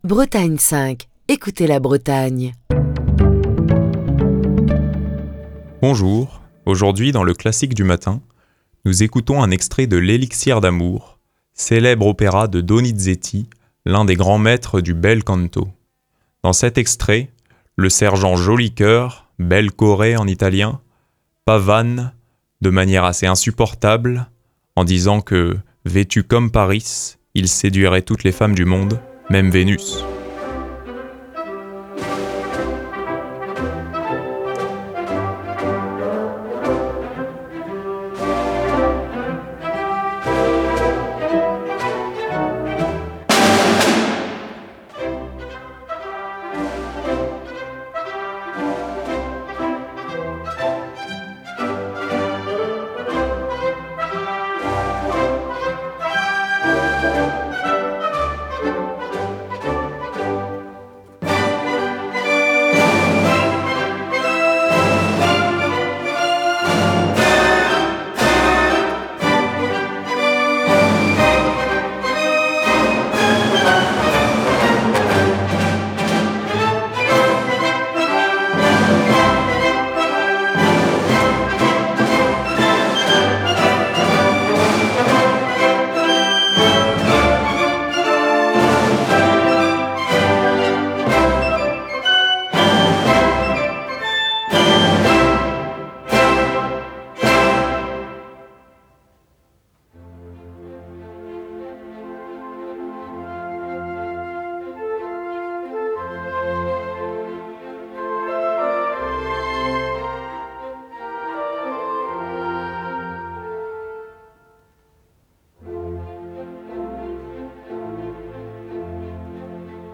opéra
bel canto
ténor